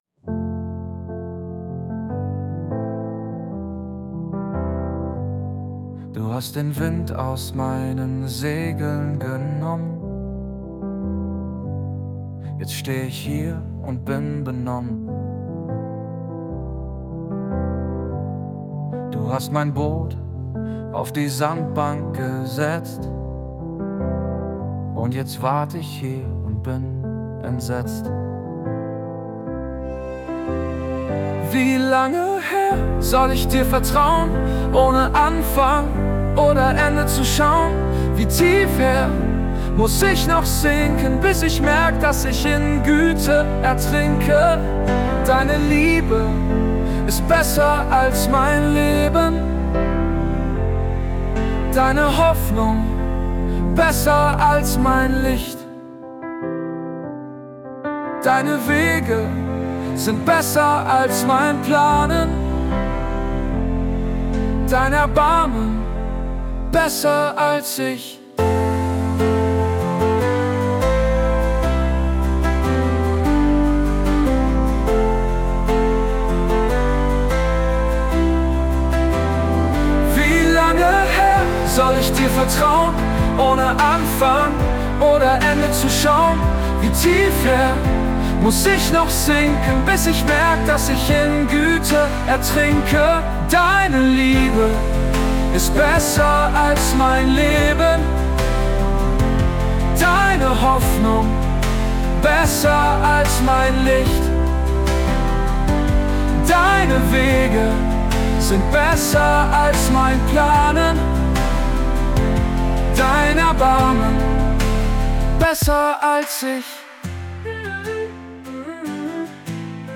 Das Jona-Lied, vertont von KI
Hier hatte ich als gewünschten Musik-Stil angegeben: male vocal, simple, singer songwriter, melodramatic, ballad, piano, cello solo parts. Nach Cello klingt es nicht, aber ansonsten prima.
Du-hast-den-Wind-aus-meinen-Segeln-genommen_Suno_v4.mp3